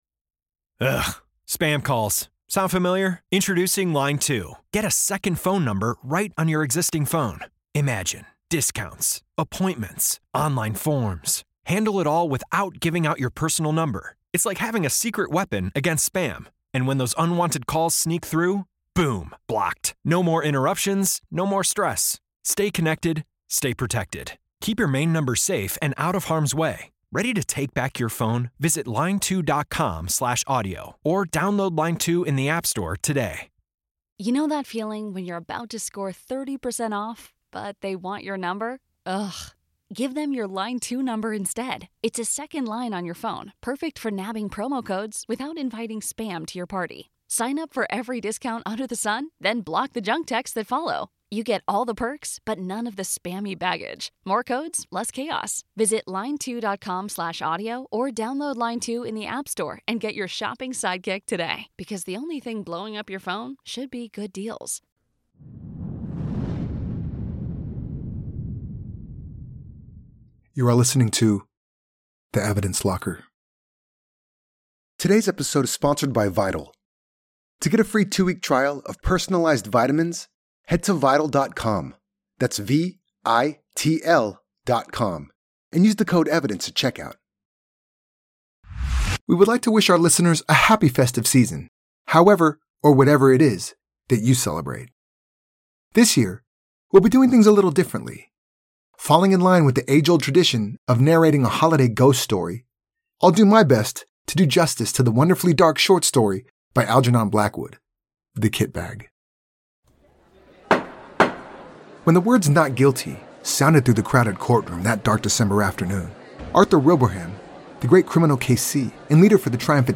True Crime, Society & Culture, Documentary, History